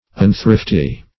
Unthrifty \Un*thrift"y\, a.